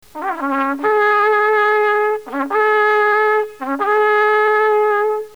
The Shavarim is (are - words ending im are plural) three rising blasts.
shavarim.mp3